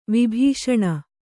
♪ vibhīṣaṇa